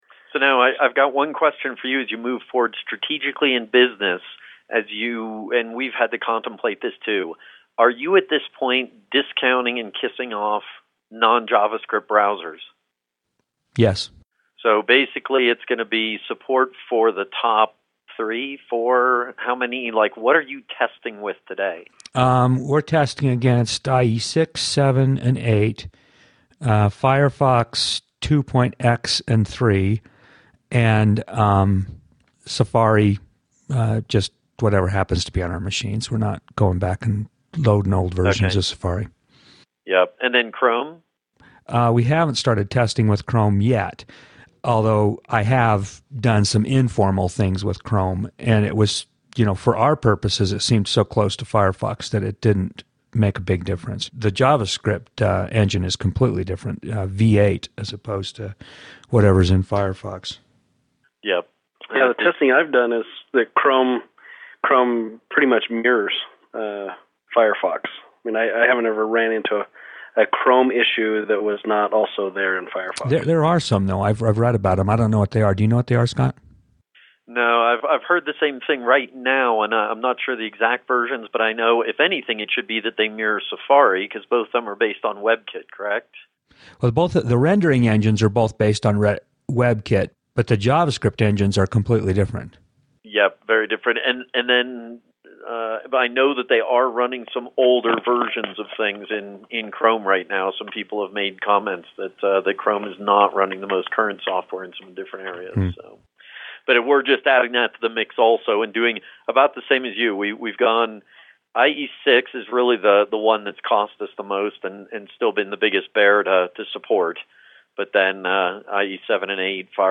Here are several developers discussing the browswers they support as of late 2008.